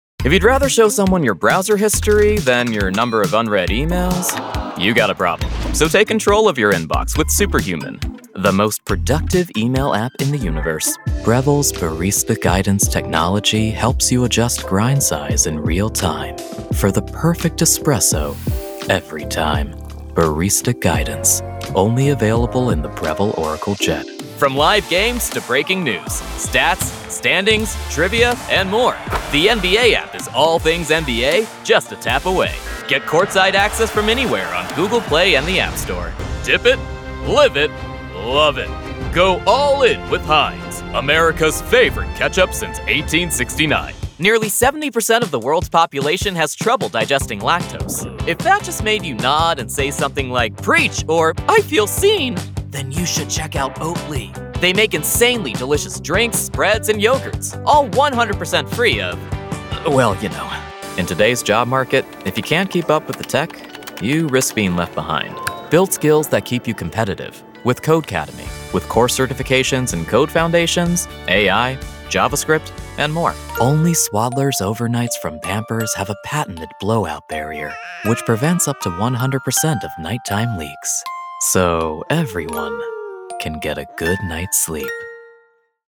Voice Actors
animation 🎬